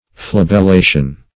Search Result for " flabellation" : The Collaborative International Dictionary of English v.0.48: Flabellation \Flab`el*la"tion\, n. The act of keeping fractured limbs cool by the use of a fan or some other contrivance.